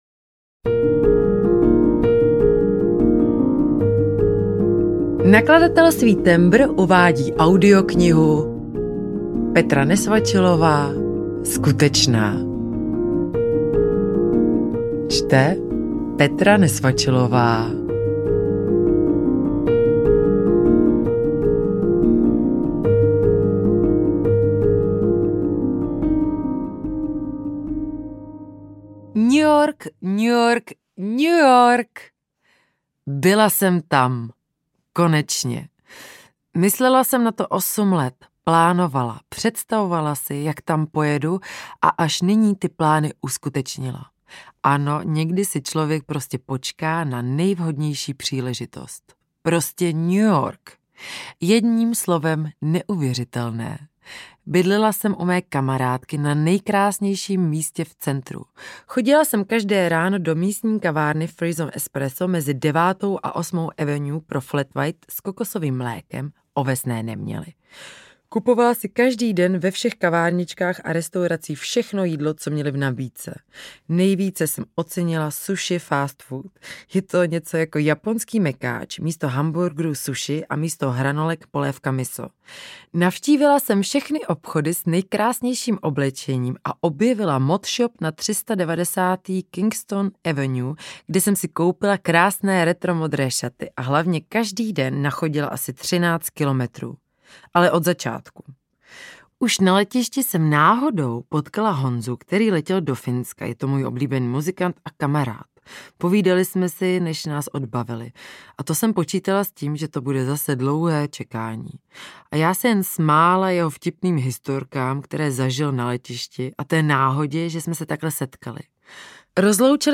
Skutečná audiokniha
Ukázka z knihy
skutecna-audiokniha